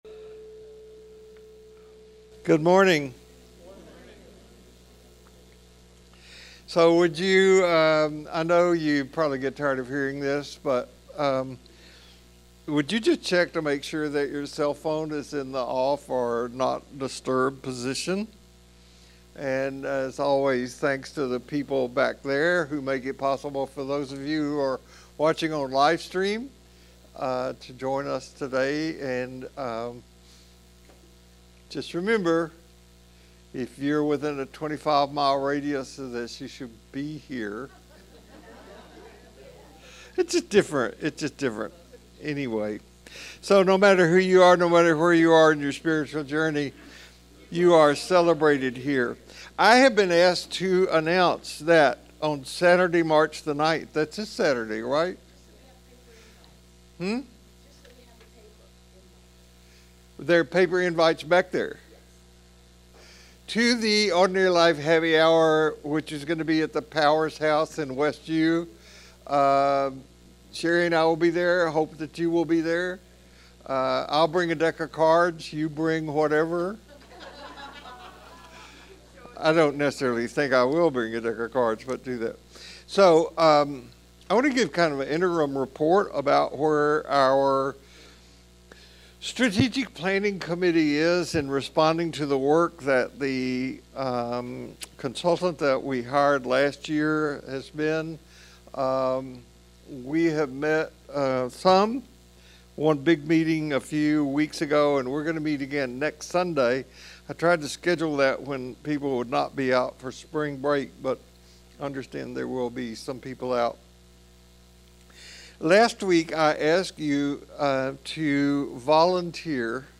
SUNDAY LECTURE | Living Beyond the Mythic Level in a World That Doesn't